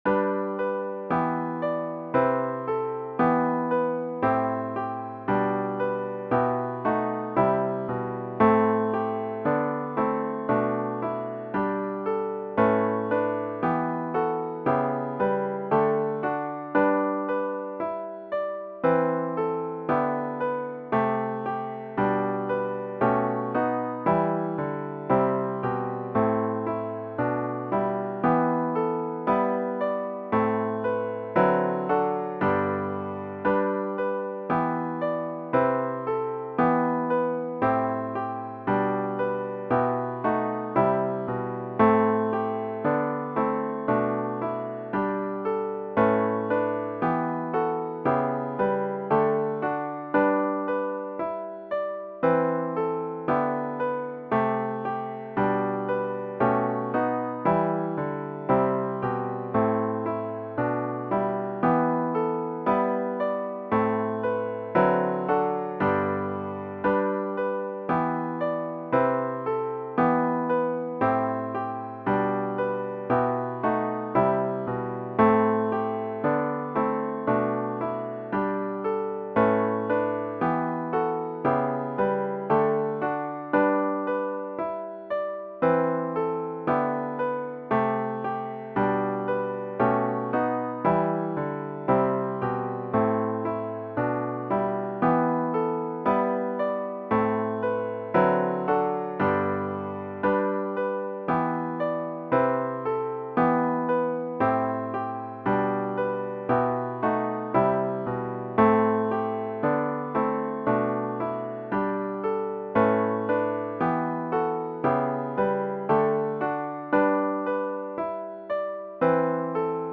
CLOSING HYMN     “Help Us Accept Each Other”     GtG 754
zz-754-Help-Us-Accept-Each-Other-4vs-piano-only.mp3